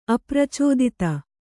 ♪ apracōdita